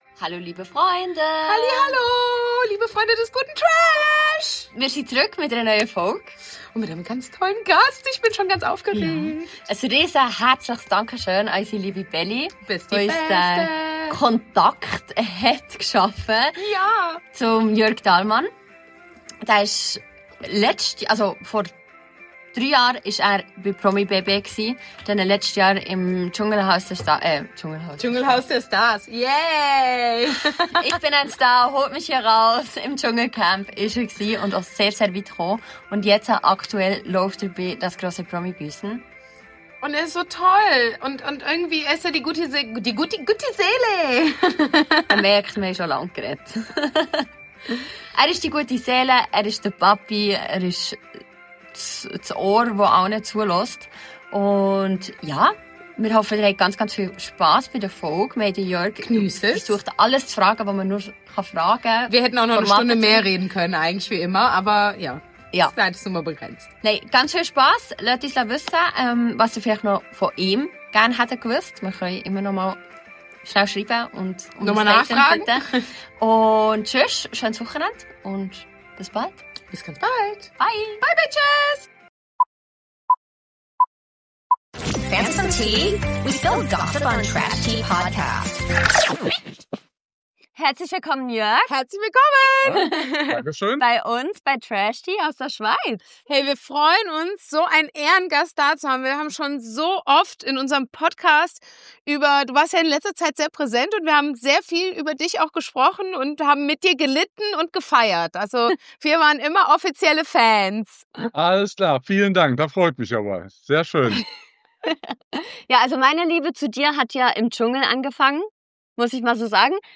The man, the myth, the legend (jedenfalls in unseren Augen) - Jörg Dahlmann war bei uns zu Gast!